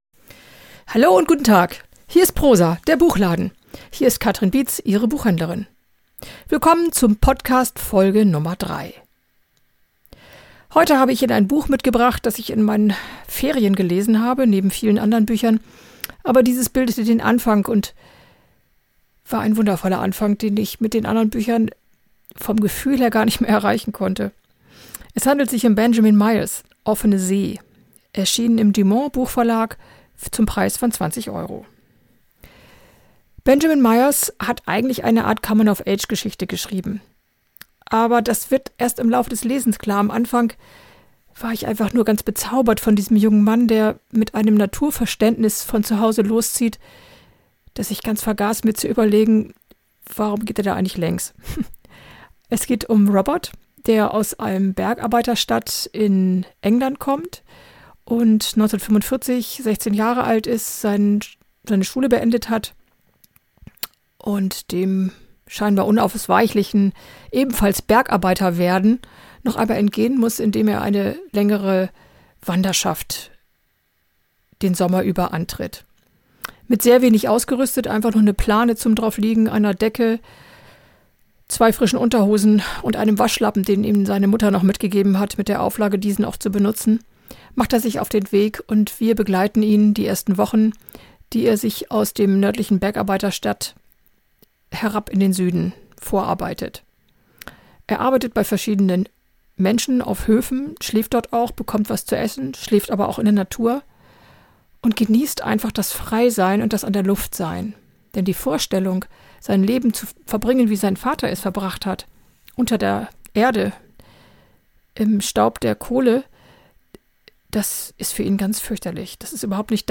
Benjamin Myers herrliches Buch „Offene See“ ist in diesem Podcast das besprochene und gelesene Buch. Vielleicht können Sie über den gelesenen Ausschnitt schon eintauchen in die Welt des jungen Wandersmannes Robert im England des Jahres 1945.